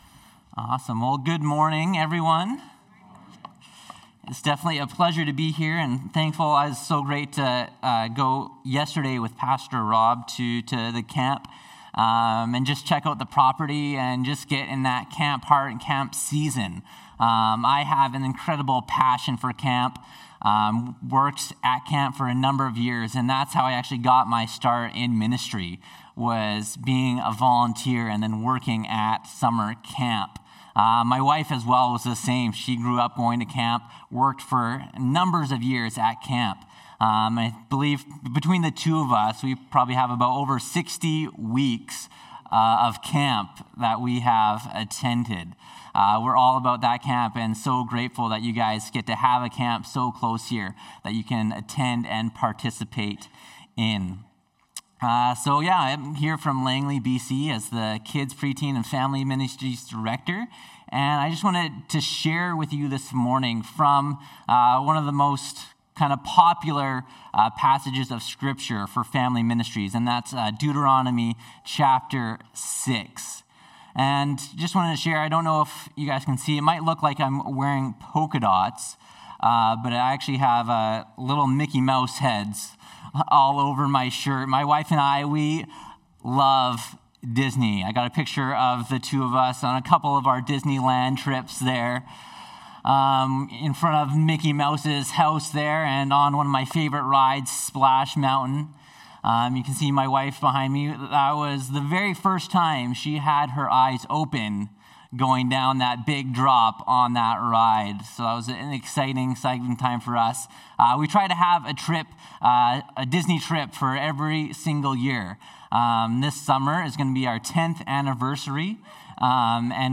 Sermons | Terrace Pentecostal Assembly